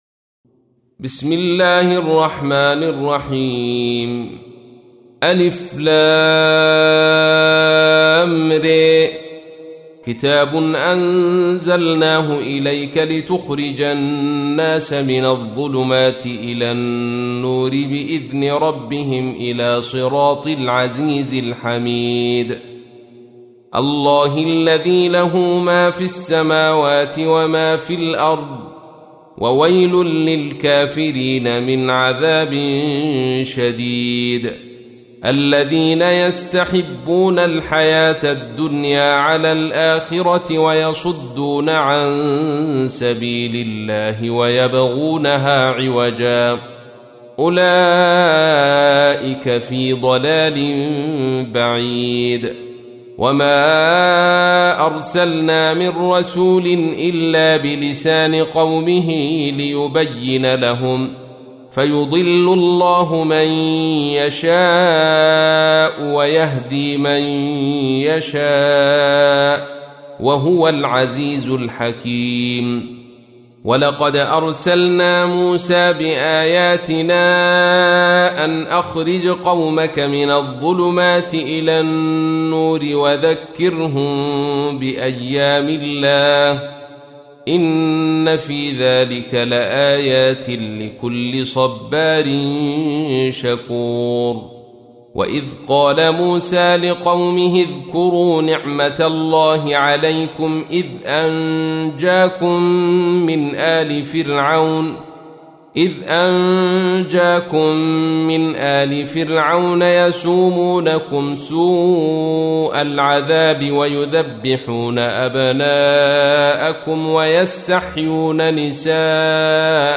تحميل : 14. سورة إبراهيم / القارئ عبد الرشيد صوفي / القرآن الكريم / موقع يا حسين